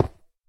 minecraft / sounds / dig / stone1.ogg
stone1.ogg